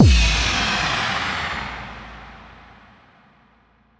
Index of /musicradar/cinematic-drama-samples/Impacts
Impact 07.wav